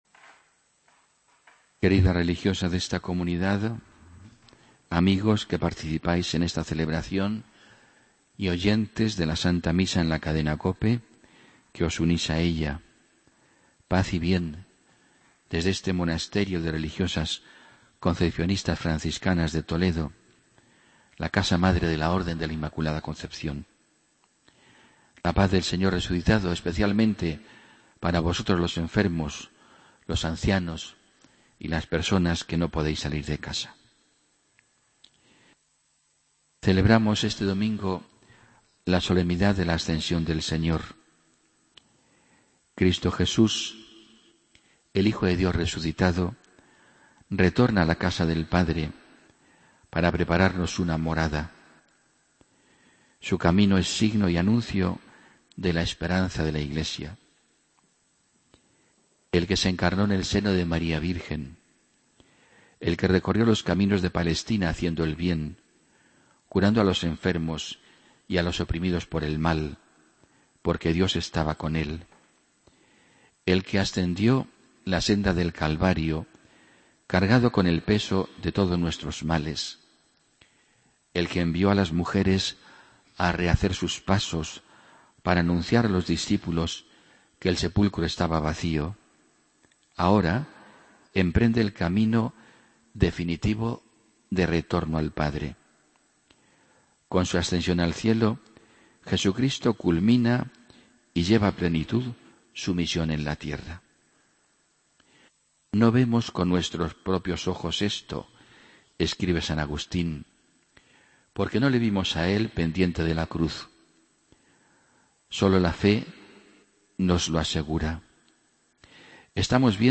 Homilia del domingo 17 de mayo de 2015